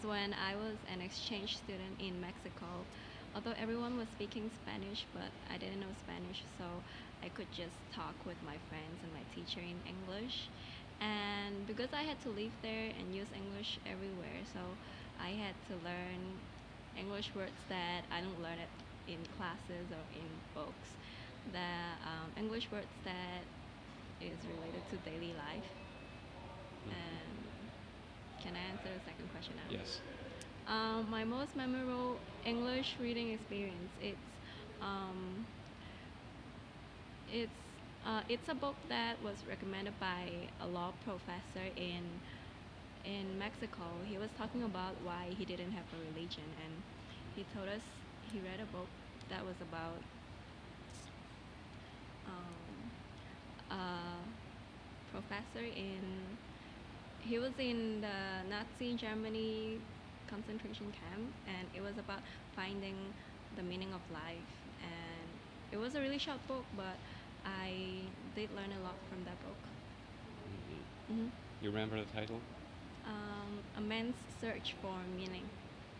Subcategory: Non-fiction, Reading, Teaching, Travel